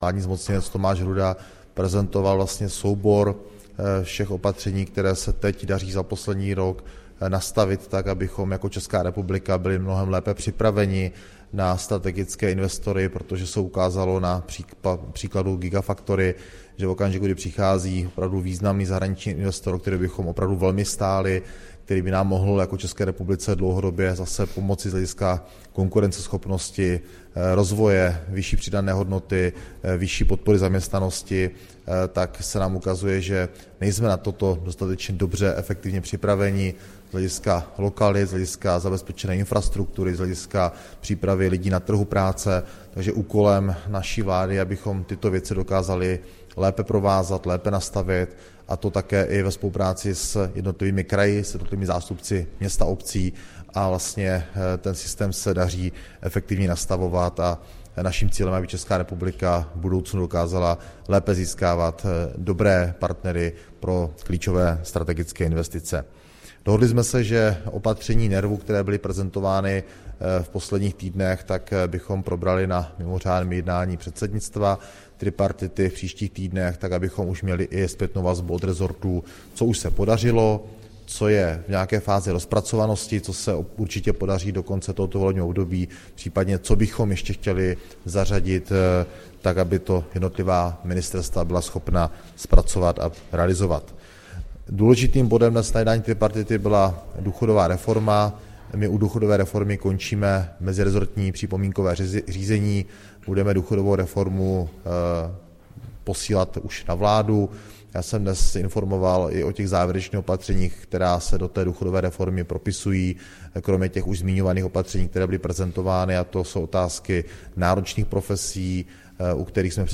Tisková konference po jednání tripartity, 26. února 2024